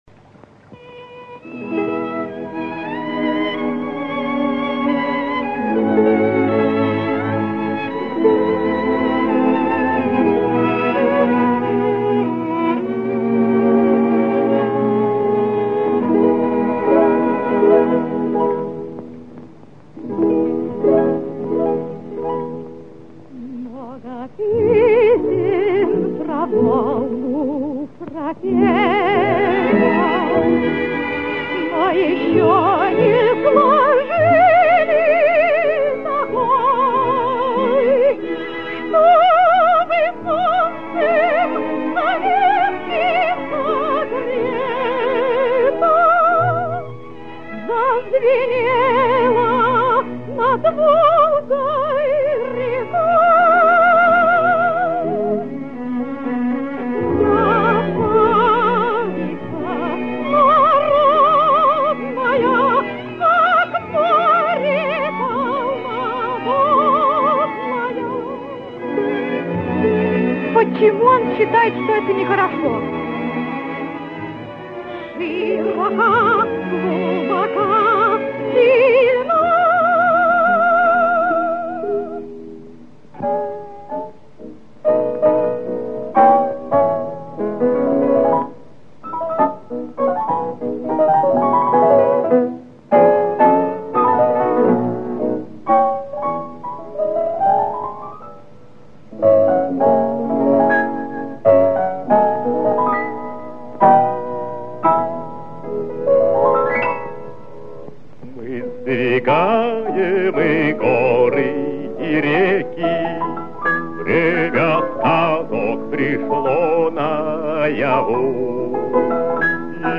Описание: Саудтрэк из кинофильма